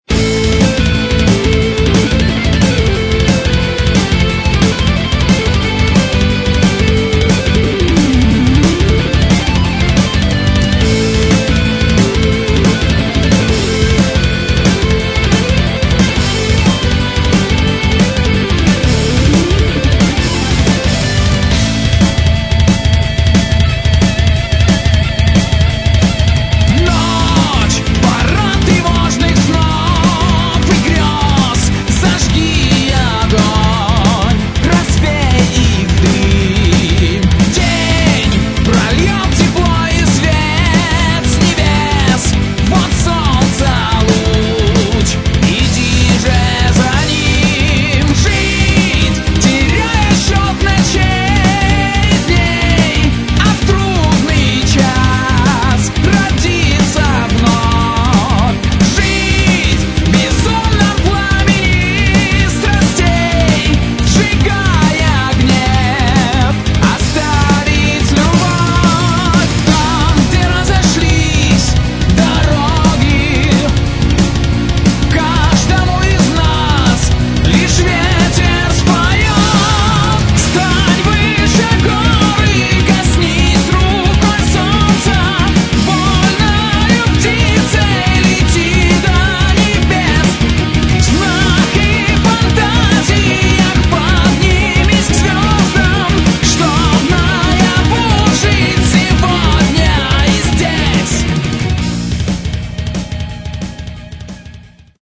Metal
гитара